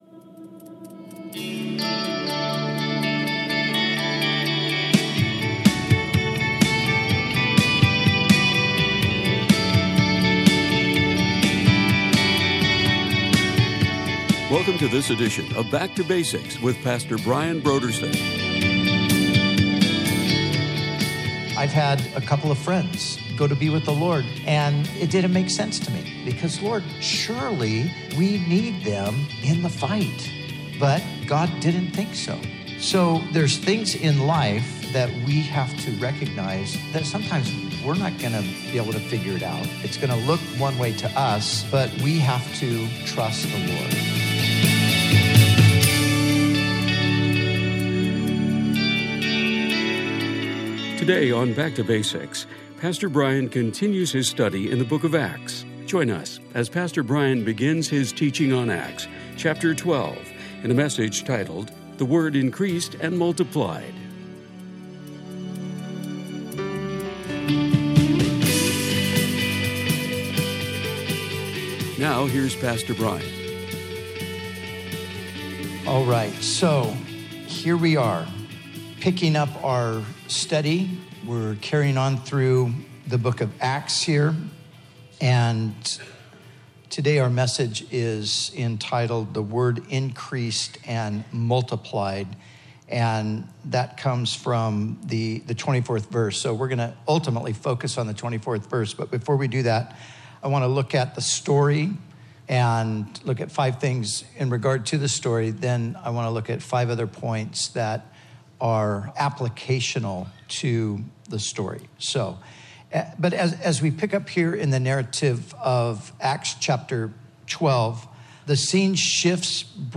Bible teaching